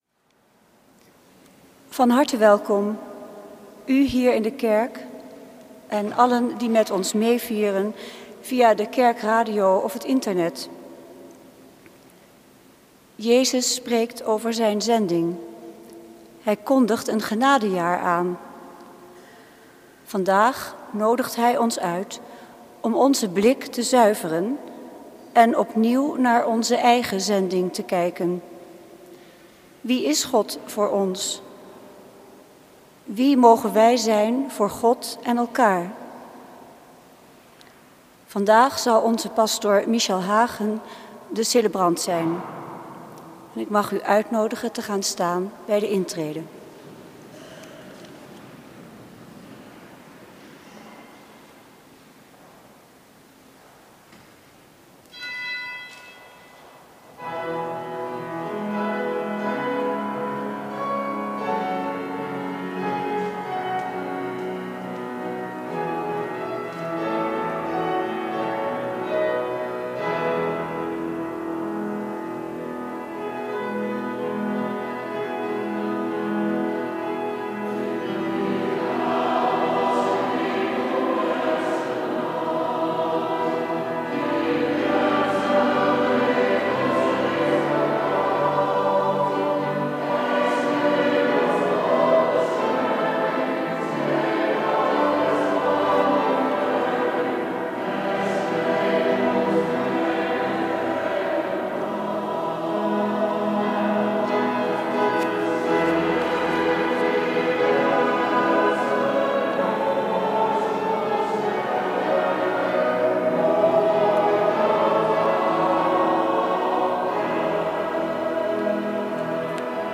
Lezingen
Eucharistieviering beluisteren vanuit de St. Willibrorduskerk te Wassenaar (MP3)